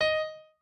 pianoadrib1_25.ogg